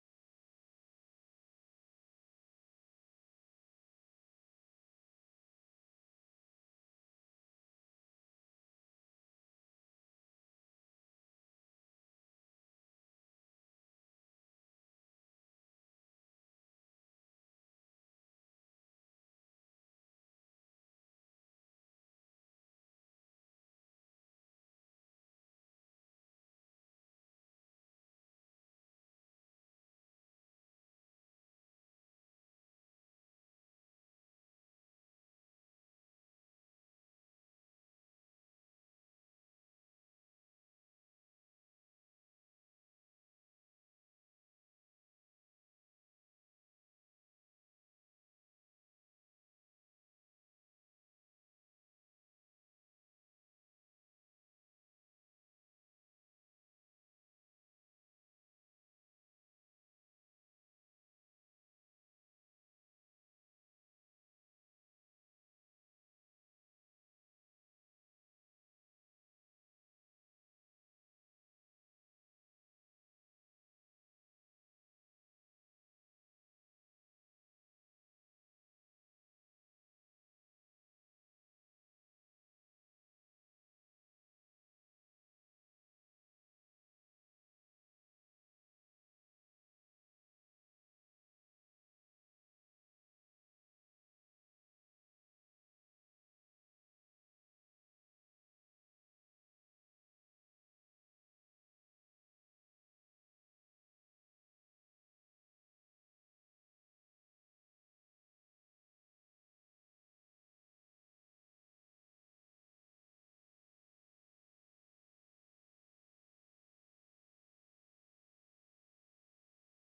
تاريخ النشر ١٧ جمادى الأولى ١٤٤٠ هـ المكان: المسجد الحرام الشيخ